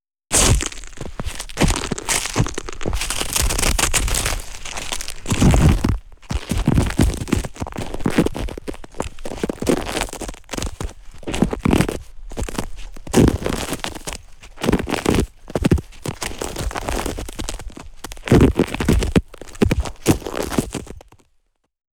• snow samples - stepping and crushing.wav
Collection of recordings on January 10th 2011 during the Georgia winter storm. Various sleds, ice breaking, ice creaking, icy tension cracks, and heavy snow crunches/impacts.
snow_samples_-_stepping_and_crushing_jbg.wav